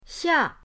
xia4.mp3